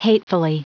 Prononciation du mot hatefully en anglais (fichier audio)